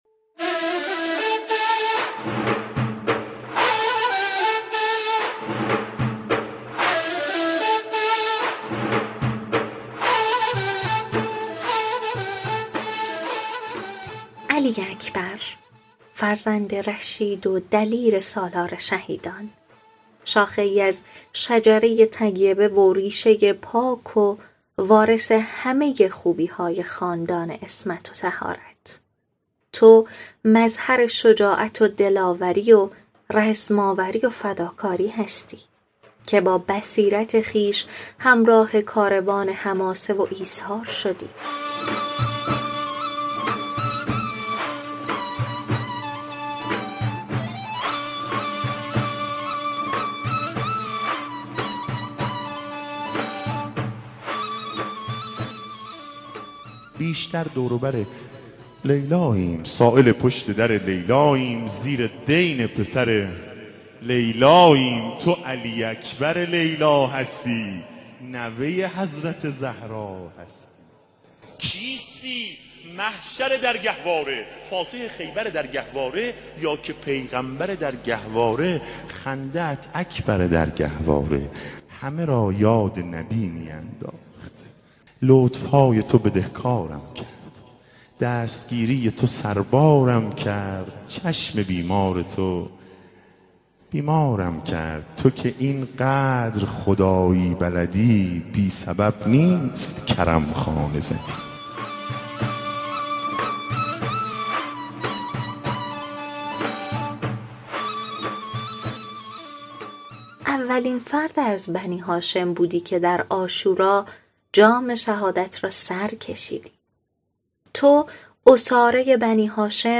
پادکستی به مناسبت ولادت حضرت علی اکبر(ع) از سوی کانون قرآن ‌و عترت دانشگاه صدا و سیما منتشر شد.